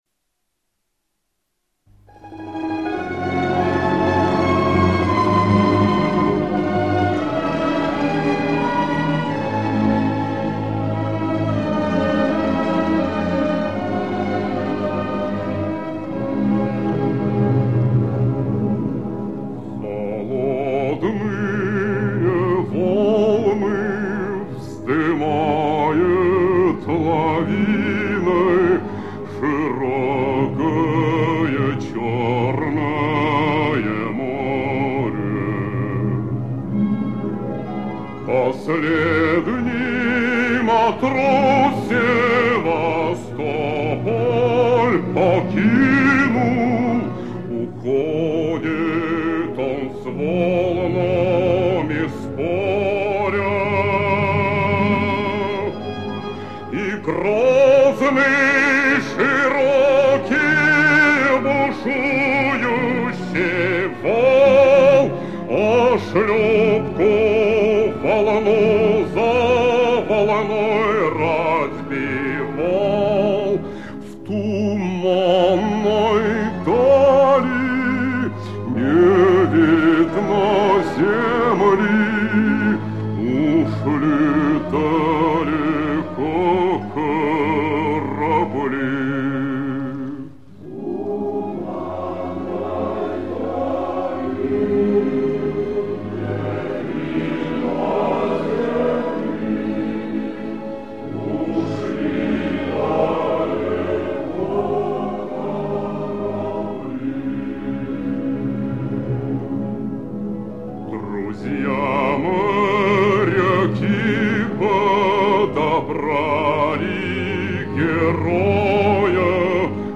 117 лет со дня рождения Марка Рейзена (03.07.1895 г – 25.11.1992 г) – российского оперного певца (бас), солиста Большого театра СССР, Народного артиста СССР.